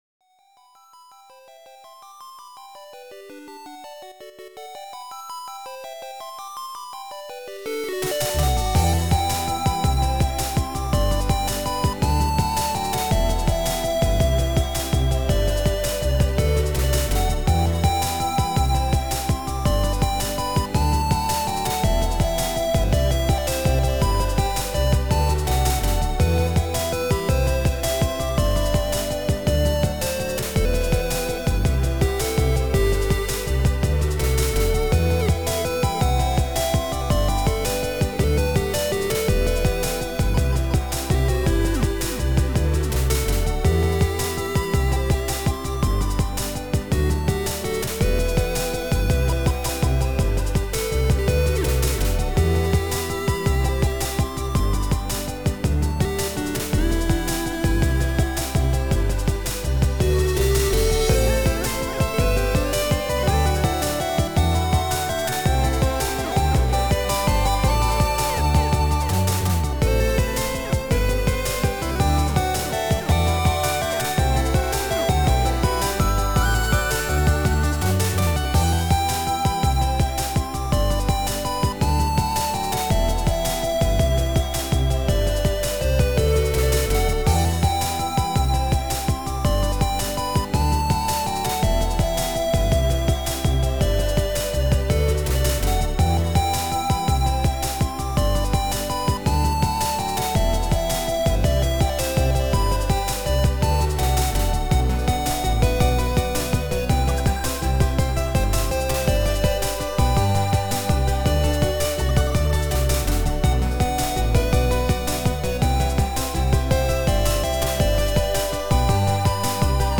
Milkytracker / xm